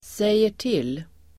Uttal: [sejer_t'il:]